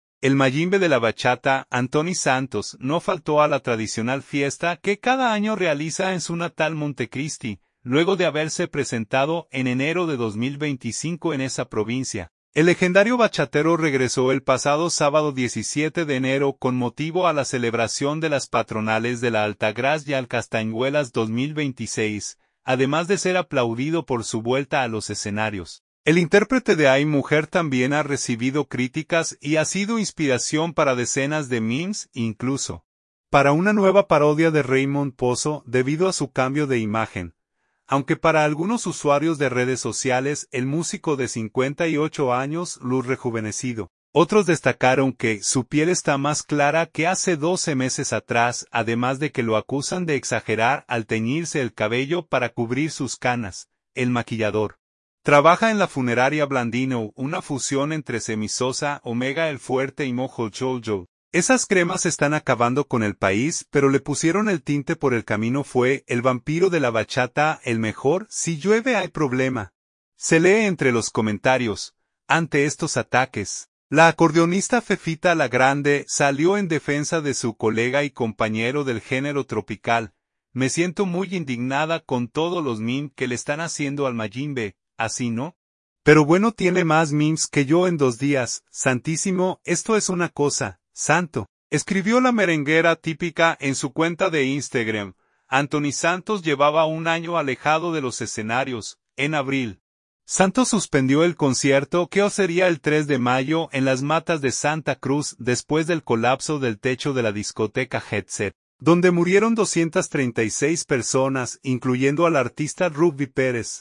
Luego de haberse presentado en enero de 2025 en esa provincia, el legendario bachatero regresó el pasado sábado 17 de enero con motivo a la celebración de las Patronales de la Altagracia - Castañuelas 2026.